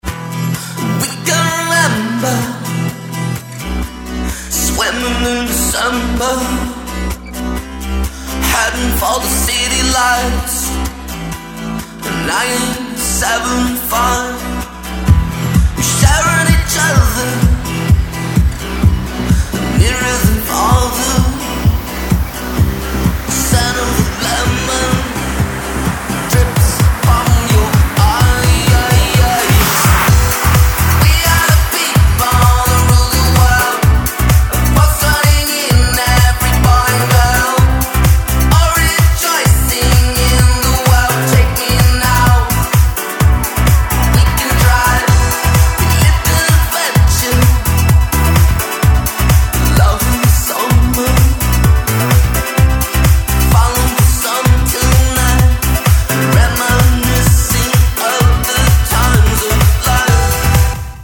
Категория: Клубные рингтоны